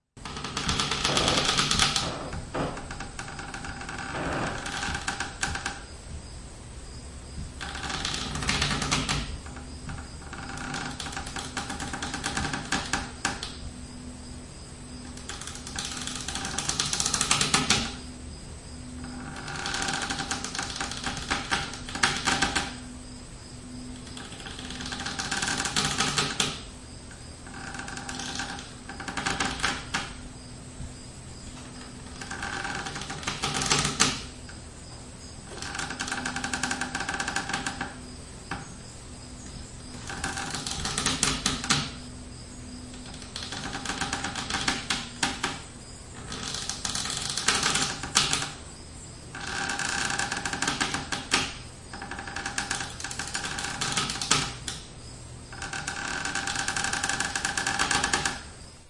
É como soa unha corda cando se tensa.
Rope_Creaking_Holding_Weight_(1).mp3